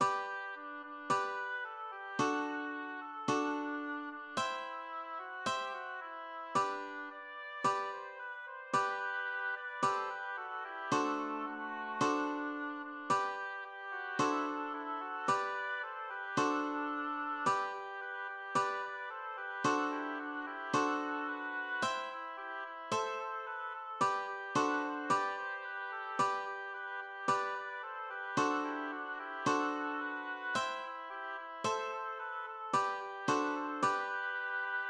Trommeln und Pfeiffen